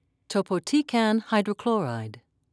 (toe-po-tee'can)